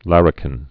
(lărĭ-kĭn)